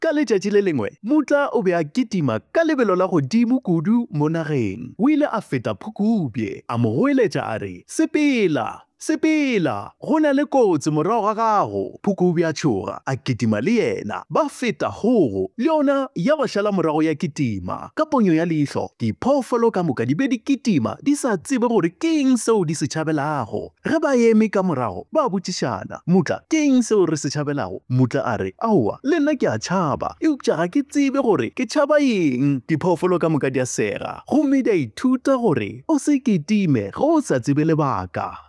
commercial, conversational, energetic, friendly, promo, soothing
My demo reels